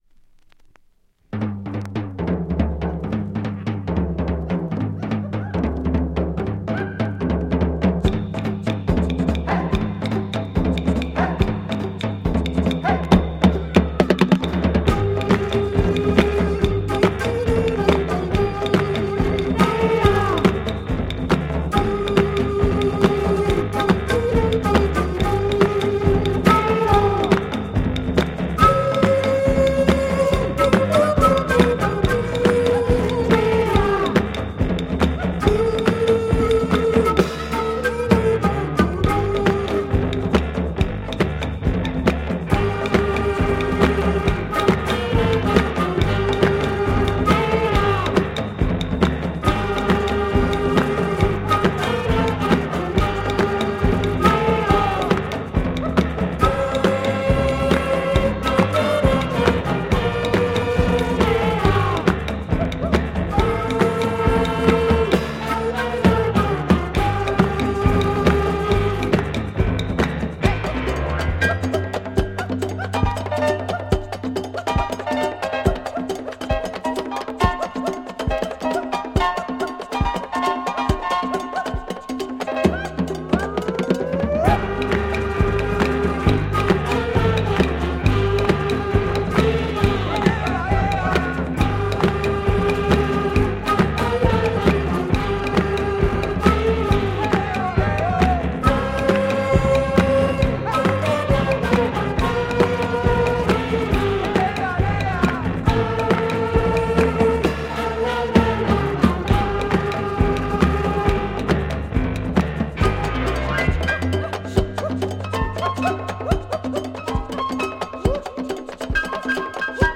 Rare Belgium Psych latin funk instro